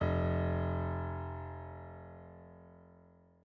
piano-sounds-dev
SoftPiano